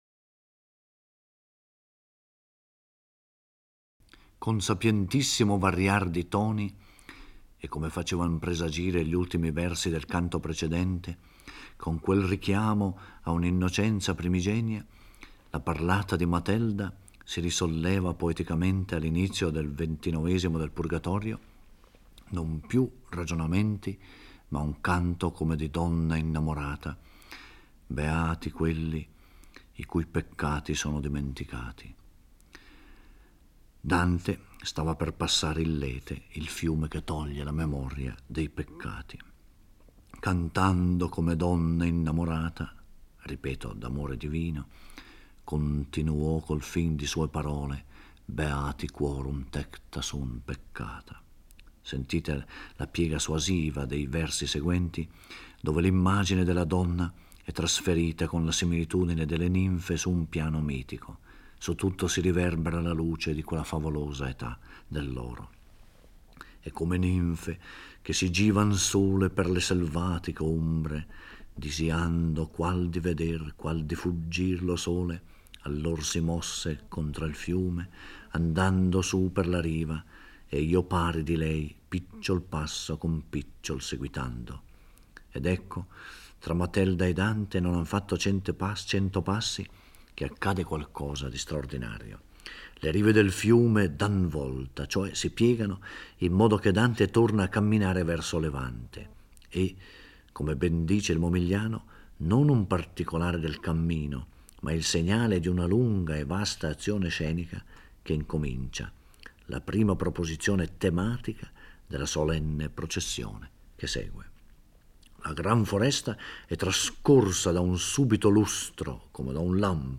Giorgio Orelli legge e commenta il XXIX canto del Purgatorio. Il canto costituisce una delle più classiche pagine di rappresentazione allegorica medievale: il topos letterario della processione, in cui con immagini concrete e riprese da testi sacri (qui in particolare l'Apocalisse di S. Giovanni) si simboleggiano e si dispongono in un preciso ordine gli elementi fondamentali della religione cristiana.